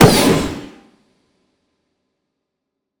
Flare gun 5-2.wav